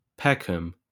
Ääntäminen
Ääntäminen UK : IPA : /pɛkəm/ Haettu sana löytyi näillä lähdekielillä: englanti Käännöksiä ei löytynyt valitulle kohdekielelle.